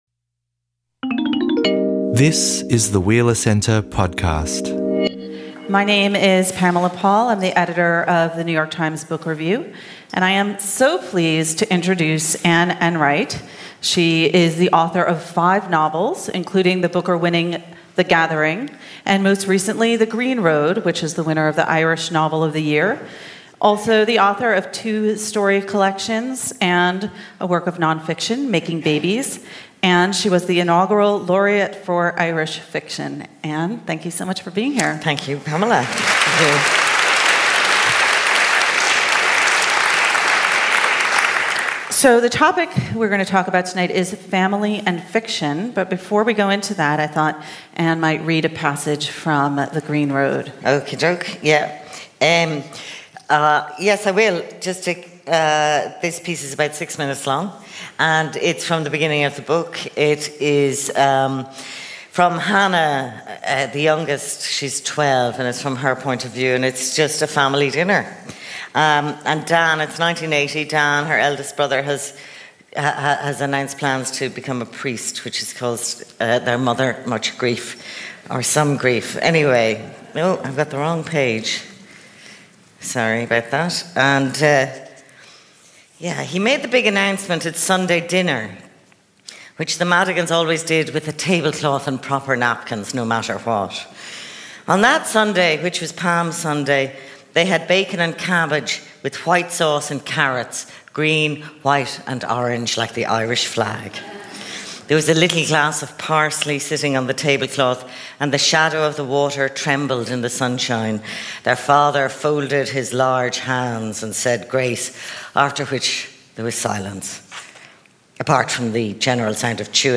Pamela Paul and Anne Enright at Northcote Town Hall